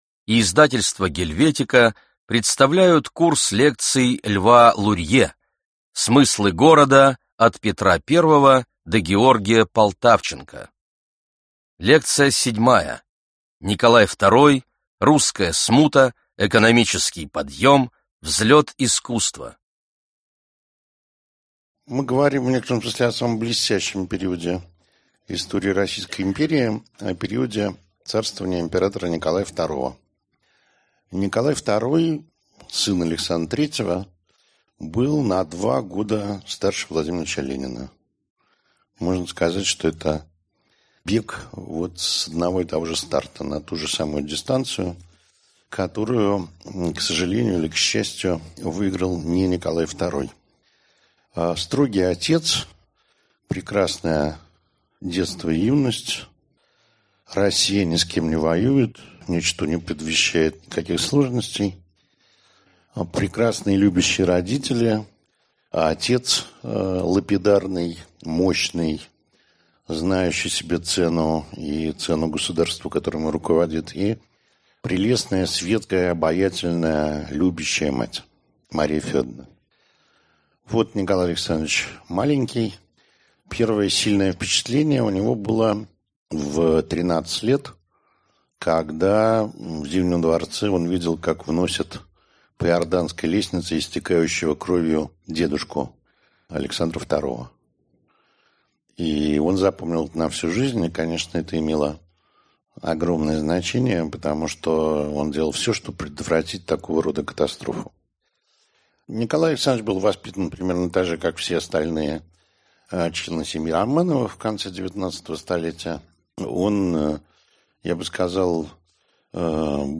Аудиокнига Лекция 7. Николай II – русская смута, экономический подъем, взлет искусства | Библиотека аудиокниг